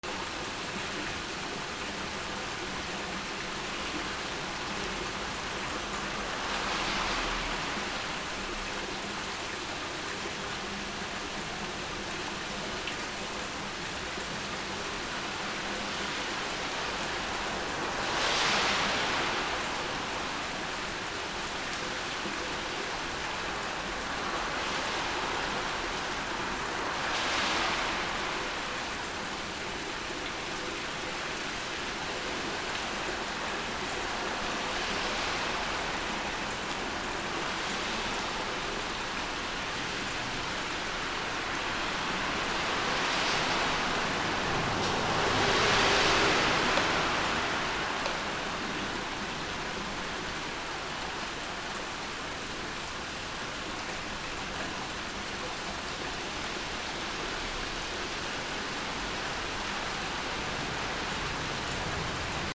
雨 排水溝 路地
/ A｜環境音(天候) / A-10 ｜雨